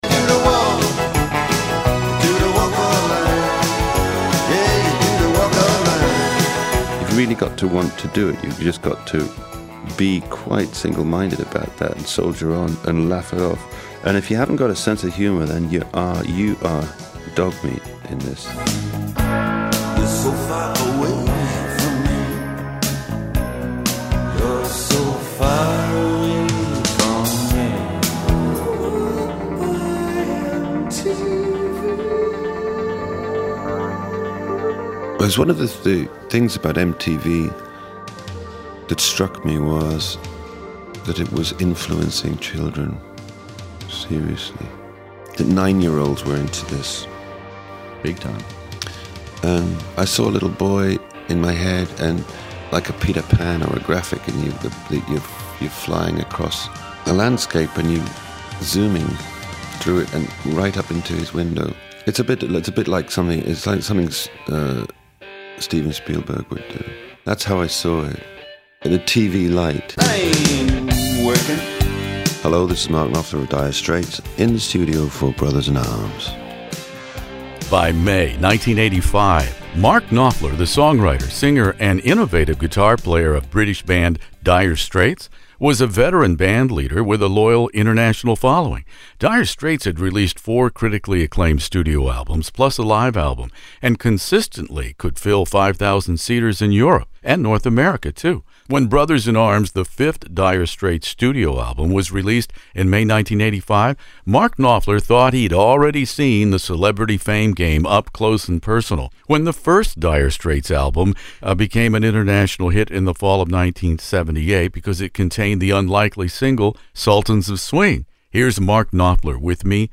Dire Straits "Brothers in Arms" interview with Mark Knopfler In the Studio